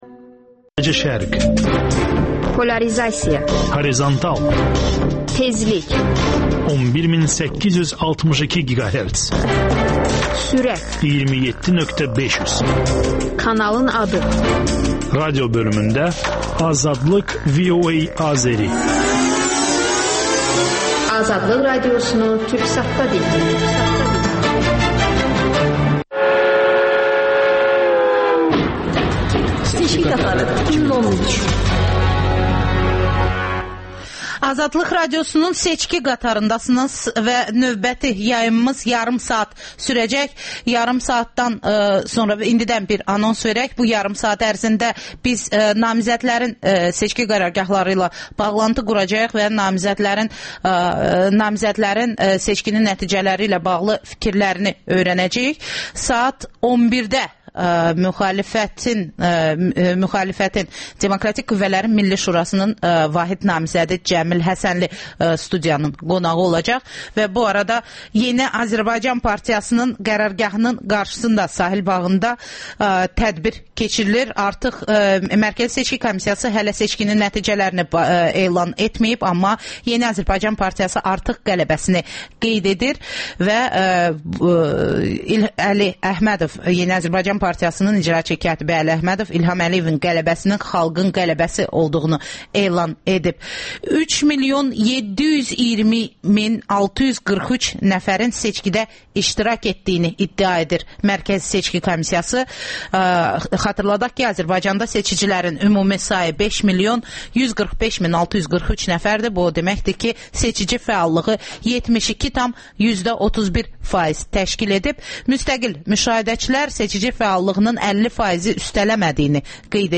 AzadlıqRadiosunun müxbirləri məntəqə-məntəqə dolaşıb səsvermənin gedişini xəbərləyirlər.